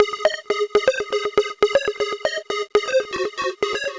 Eurofision Ab 120.wav